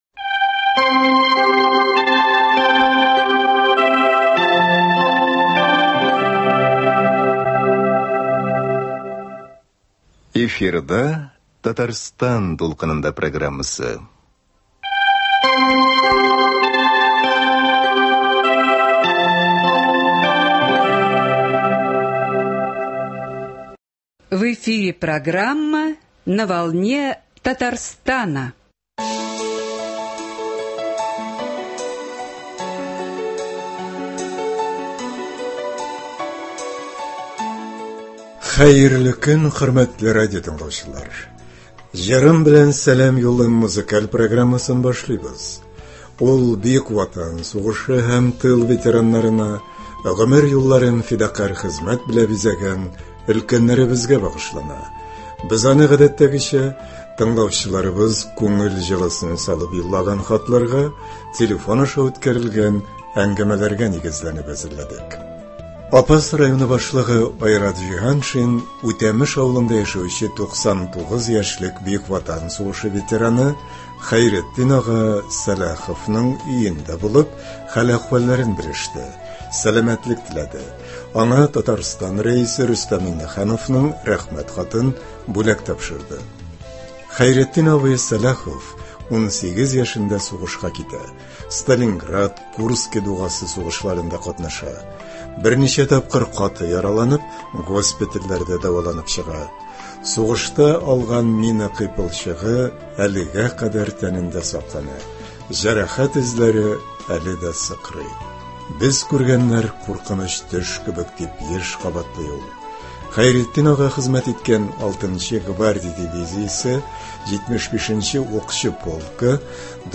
Ветераннар өчен музыкаль программа.